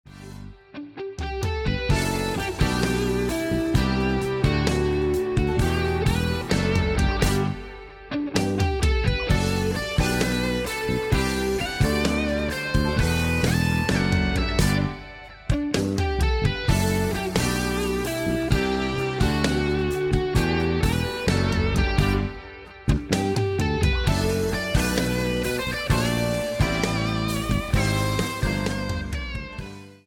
A play-along track in the style of fusion.